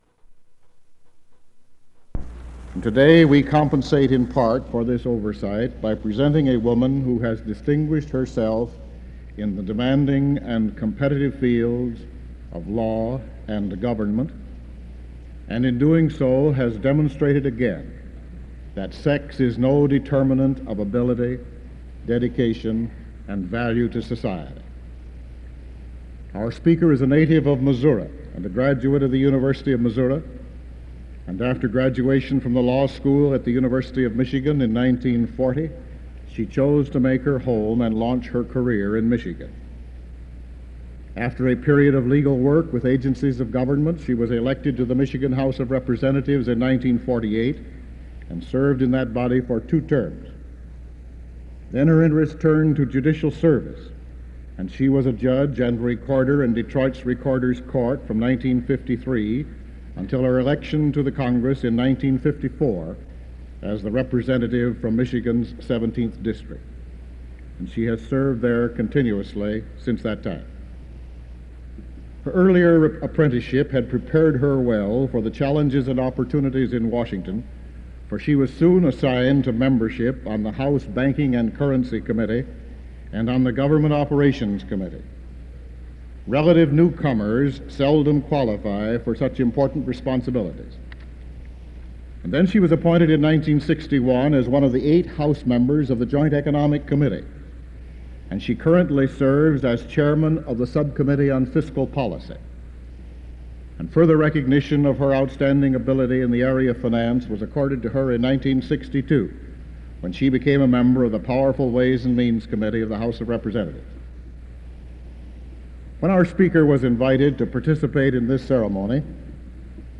Commencement Address, Winter 1966
Commencement Address, Winter 1966 Back Creator: WKAR Subjects: Alumni, Faculty, Students, Presidents, Vietnam, Commencements Description: MSU President John A. Hannah presents The Honorable Martha W. Griffiths, Member of Congress, Seventeenth District of Michigan, as the speaker of the Winter 1966 Commencement. Mrs. Griffiths speaks to the graduating class about Vietnam, the issue of inflation, defense spending, and her acknowledgement of the leadership potential of the graduating class. NOTE: A small portion of the beginning of Hannah's introduction is missing.